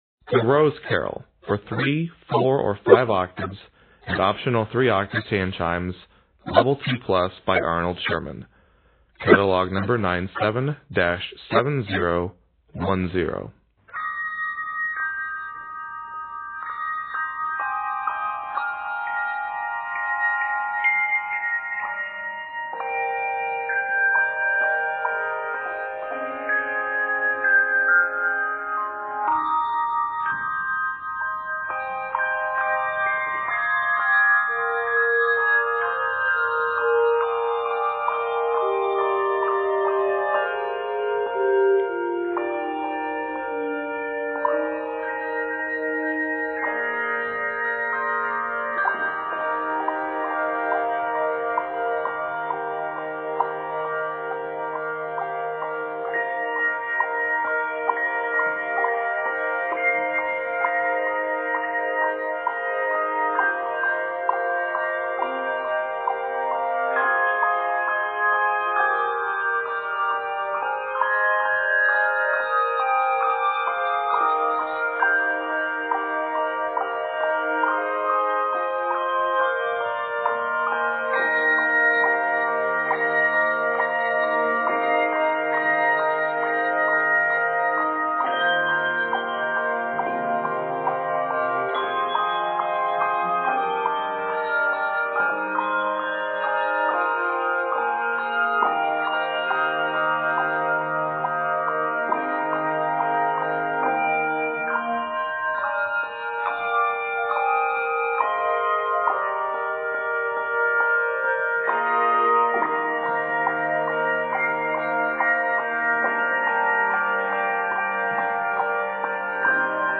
beautiful and expansive meditative arrangement
Octaves: 3-5